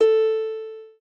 lyre_a.ogg